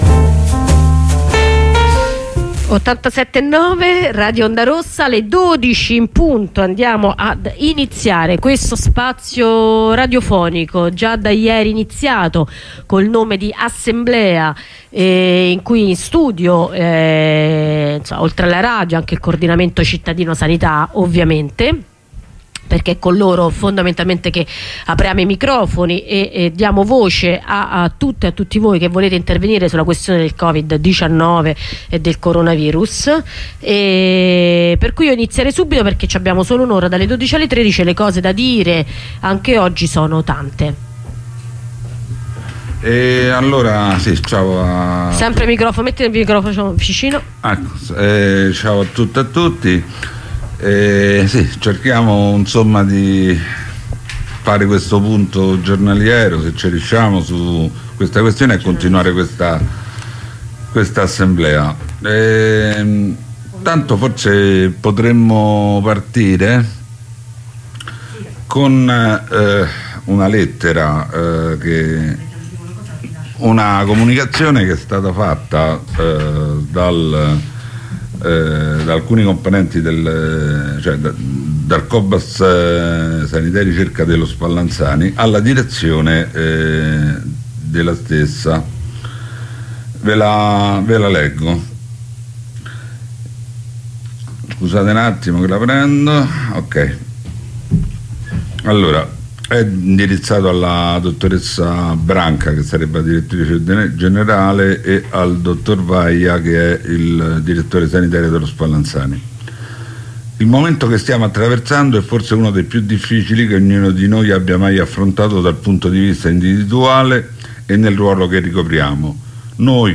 Assemblea radiofonica: 2° giorno
Oggi tanto gli interventi dagli infermieri e da chi lavora nella scuola.